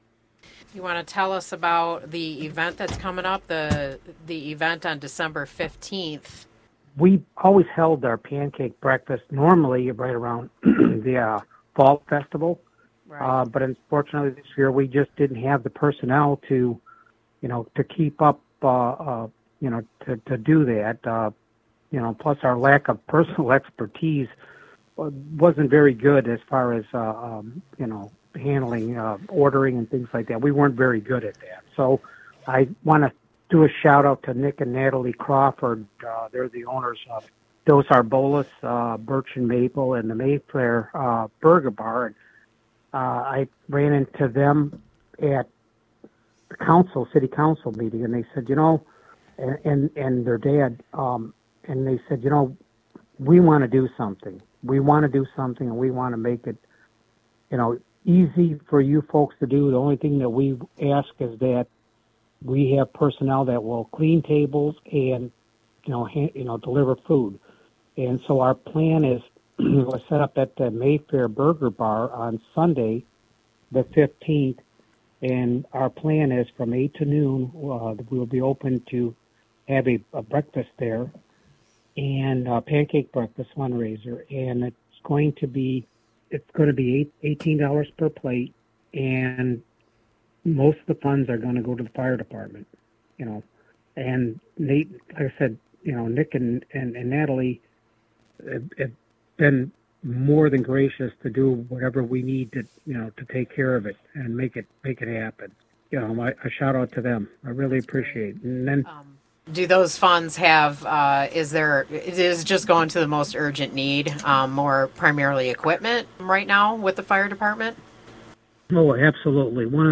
Fire-Dept-interview.mp3